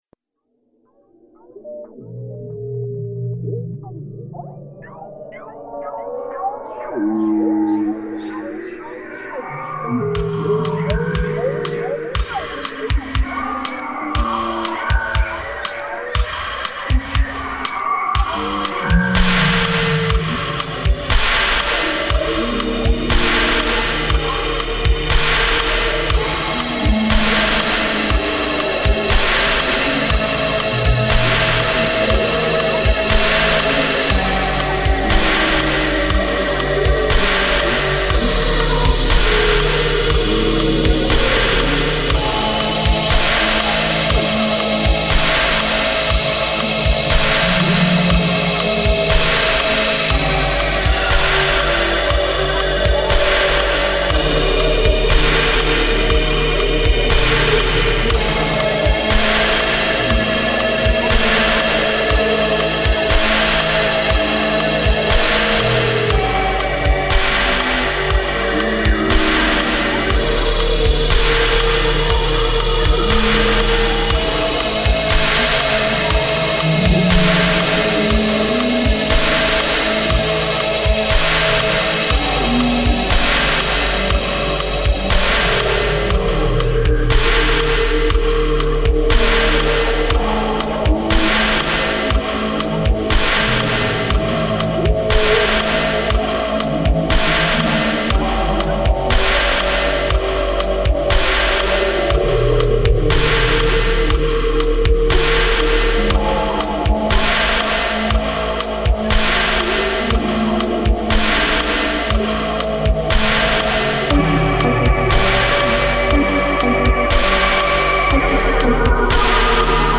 horror movie music. its old!